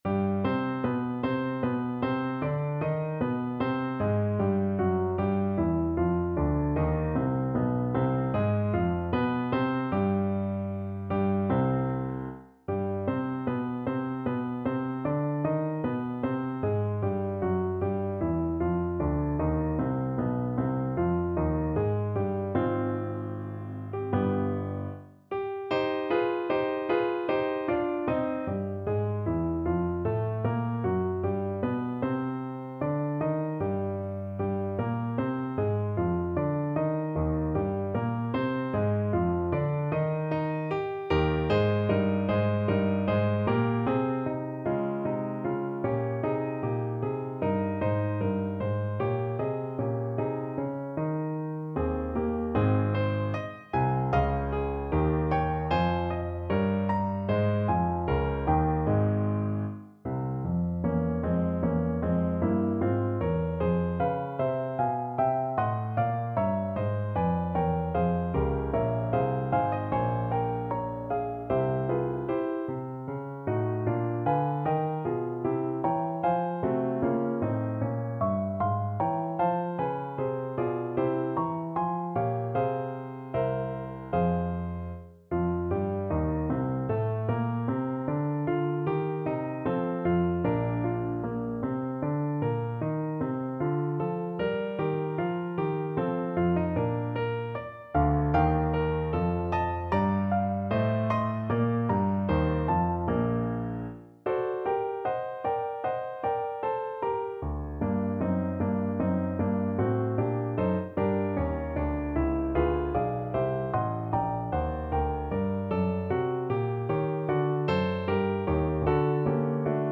Larghetto (=76)
Classical (View more Classical Saxophone Music)